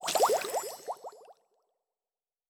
Potion and Alchemy 12.wav